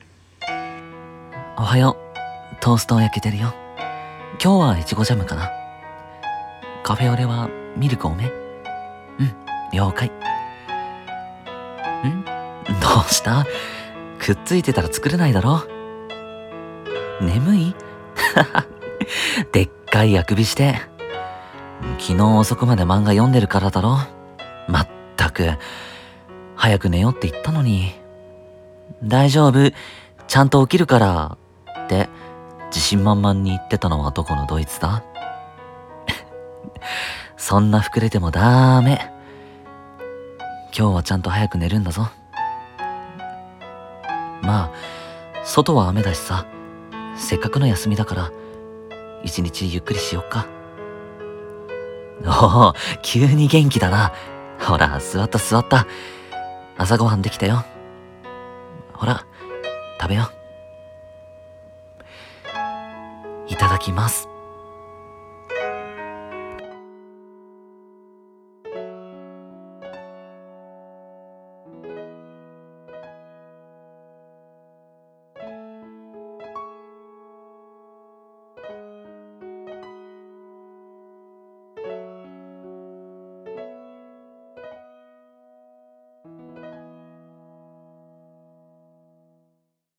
【声劇】休日の朝、僕は君を甘やかす